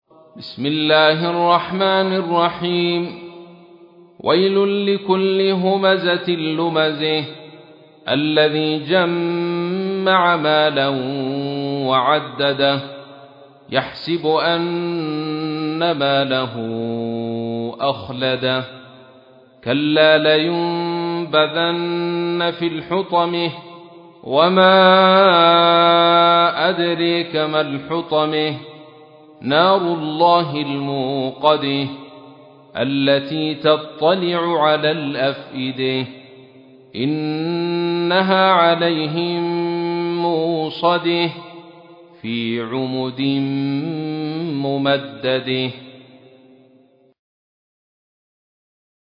تحميل : 104. سورة الهمزة / القارئ عبد الرشيد صوفي / القرآن الكريم / موقع يا حسين